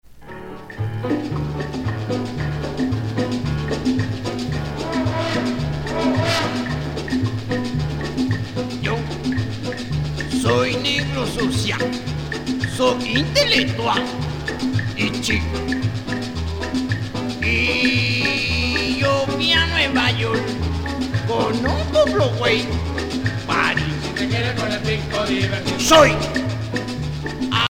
danse : rumba
Pièce musicale éditée